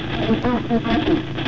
registrazione con ricevitore BC 312 in onde corte alla frequenza di 12.0 Mhz